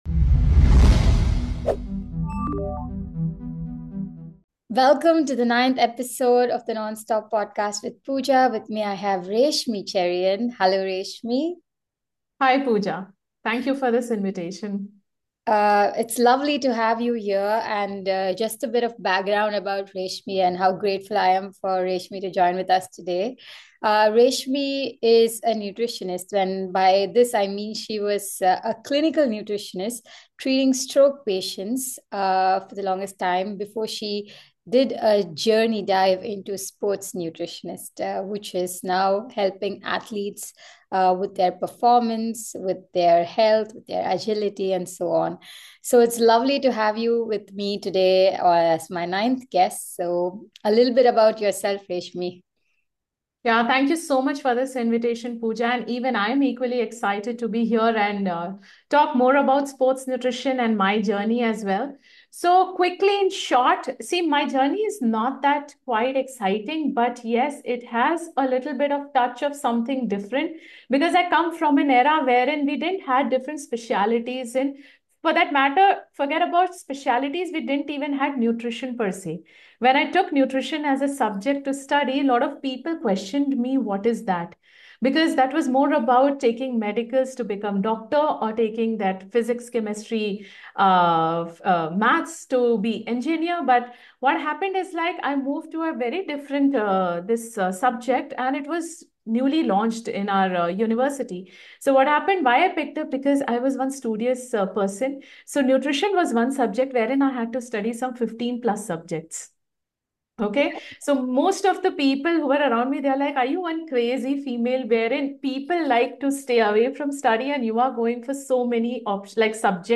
a motivating conversation about the vital role of nutrition in overall wellness